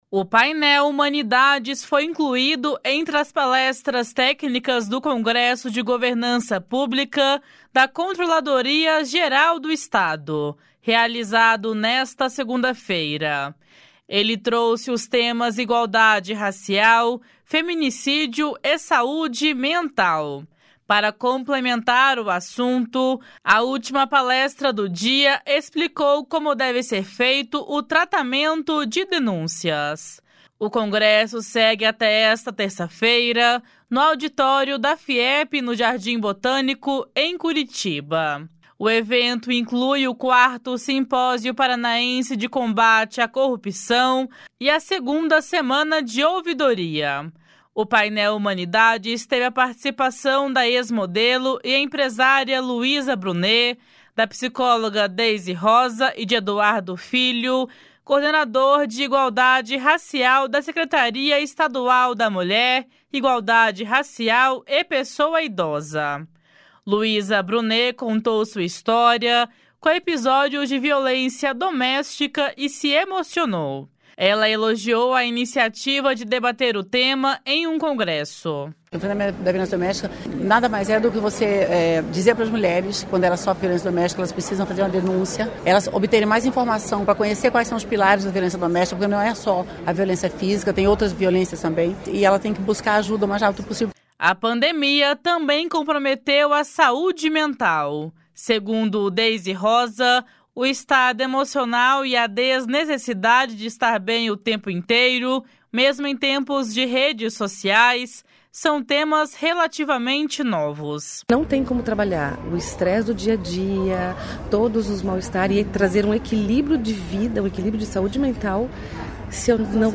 Luiza Brunet contou sua história, com episódios de violência doméstica e se emocionou.
// SONORA LUIZA BRUNET //